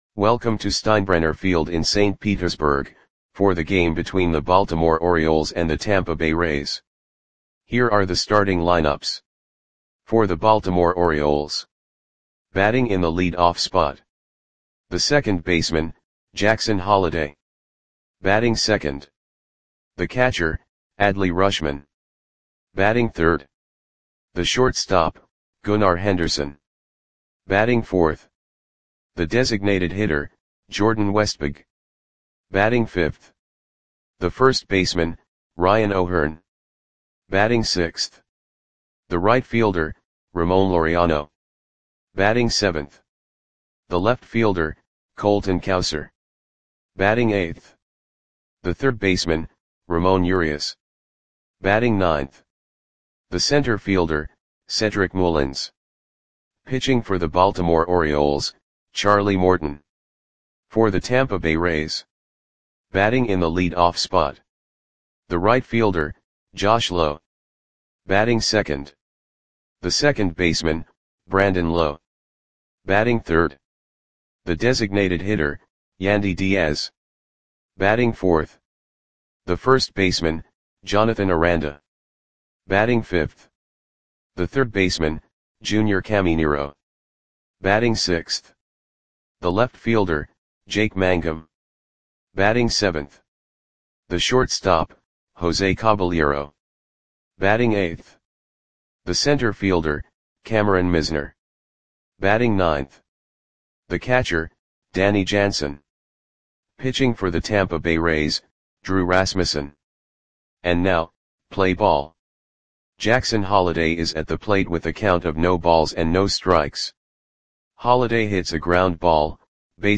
Audio Play-by-Play for Tampa Bay Rays on June 19, 2025
Click the button below to listen to the audio play-by-play.